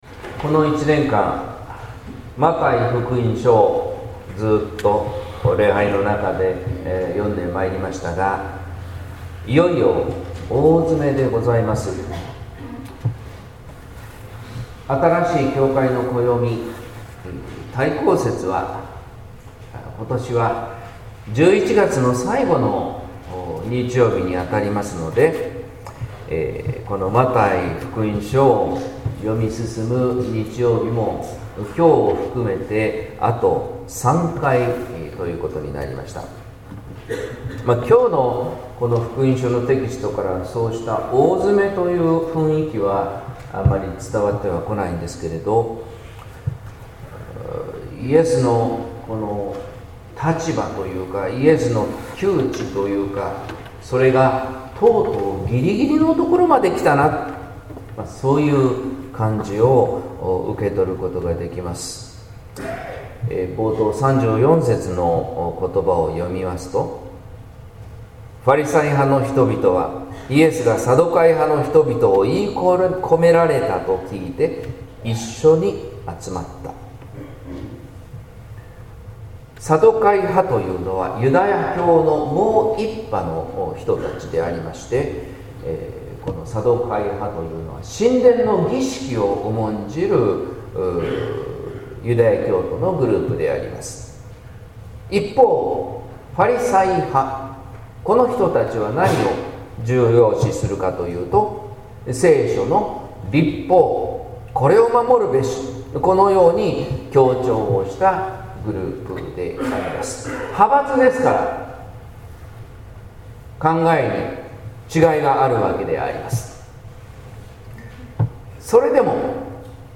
説教「いのちの形」（音声版） | 日本福音ルーテル市ヶ谷教会